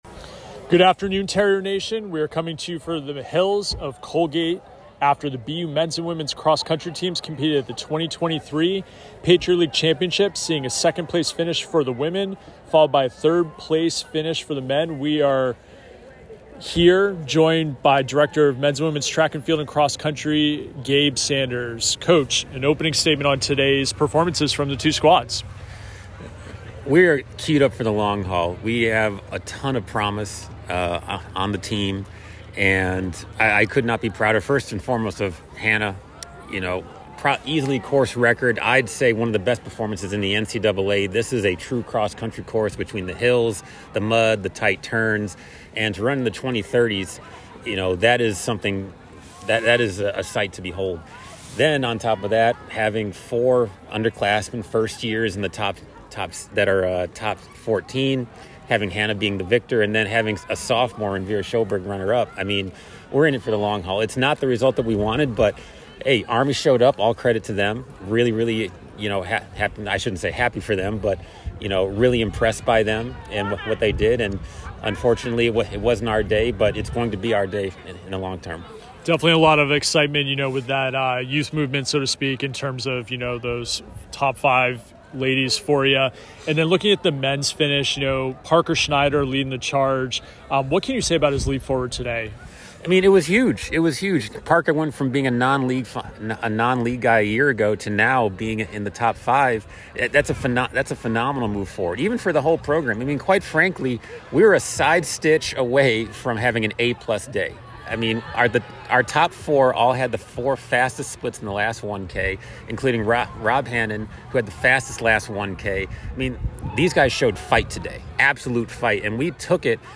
Cross Country / Patriot League Championships Postrace Interview